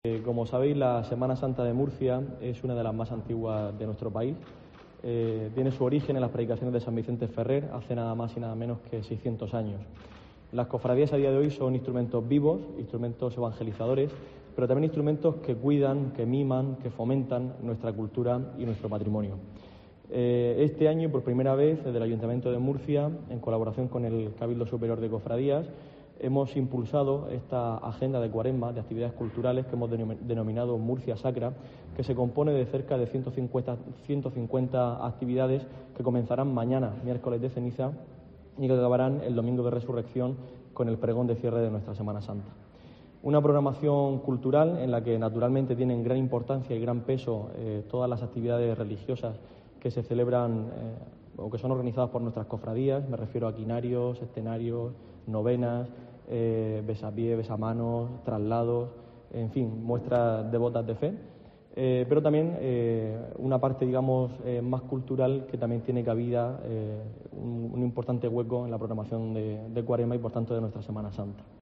Diego Avilés, concejal de Cultura e Identidad